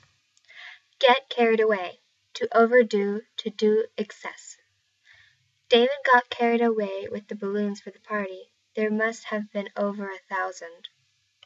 get carried away は、この二つ目の意味に当たるイディオムであり、考えや行動において、感情や情熱によって我を忘れて夢中になったり、調子に乗ったりするということを言います。 英語ネイティブによる発音は下記のリンクをクリックしてください。